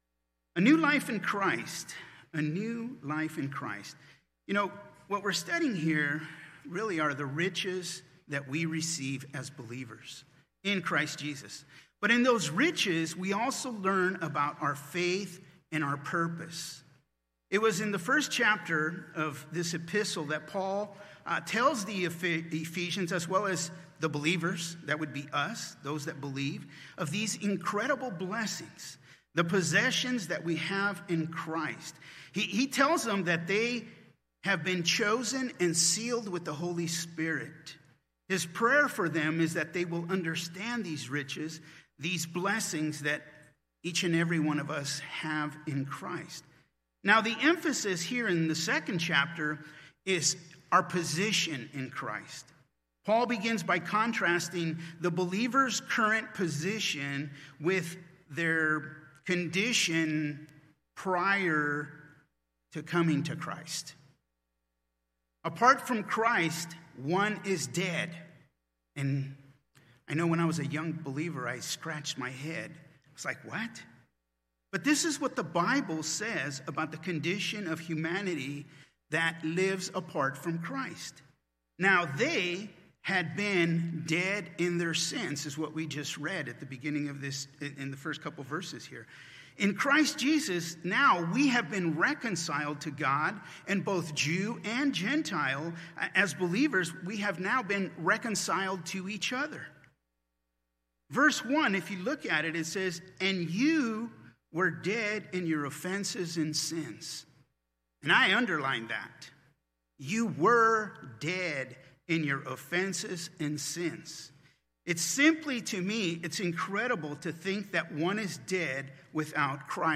Calvary Chapel Rialto – Sermons and Notes